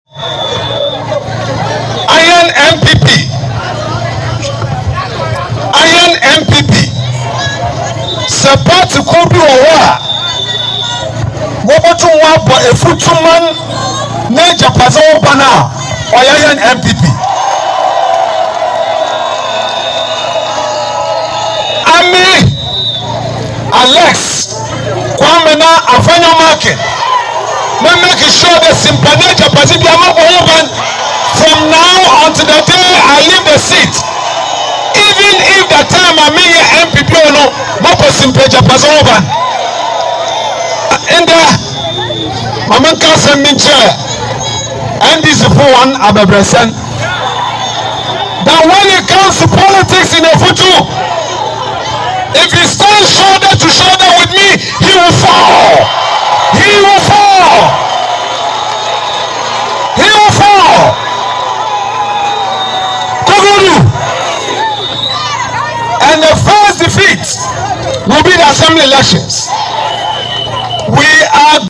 He said this at an “NPP All White Peace Walk” held on December 8 at Effutu, where he urged the crowd to ignore the NDC’s lies and vote for him in 2024.